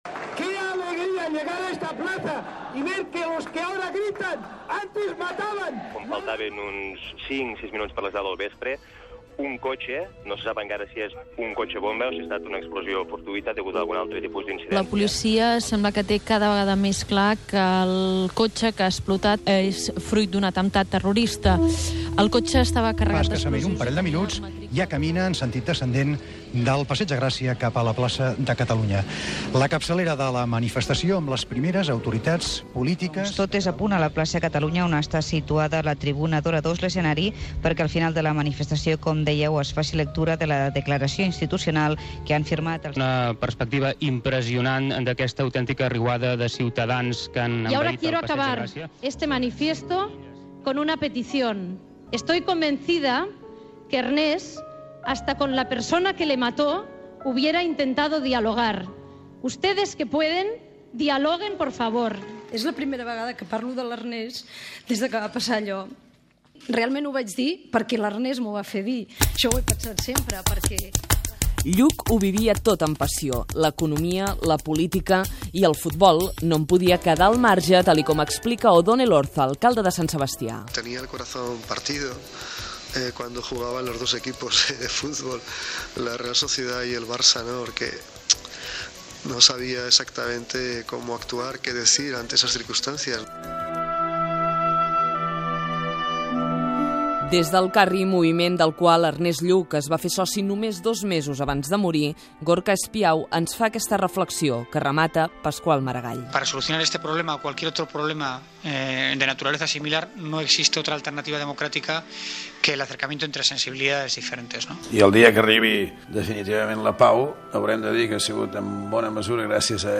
Reportatge, recollint diversos parlaments i declaracions, recordant el primer aniversari de l'assassinat del polític Ernest Lluch per ETA, a Barcelona.
Informatiu